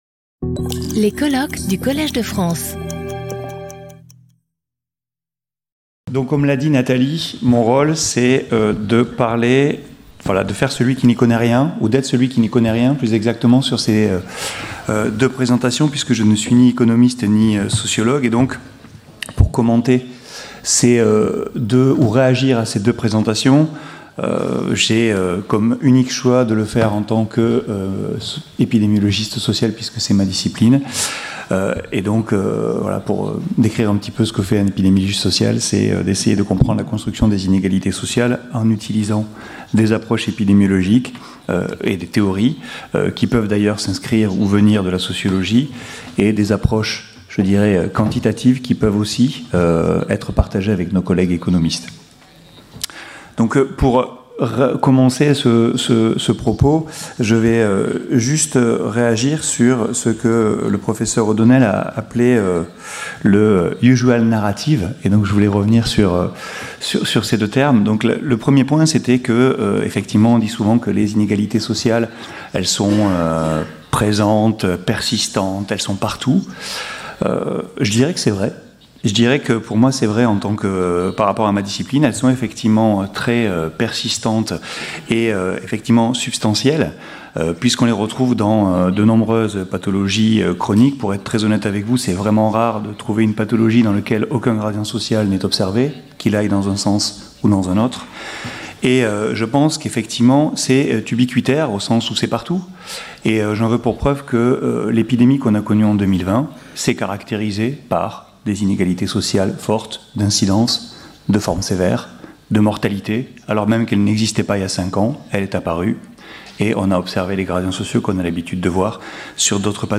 Discussion | Collège de France